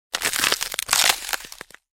Звуки льда
Ломаем лед пополам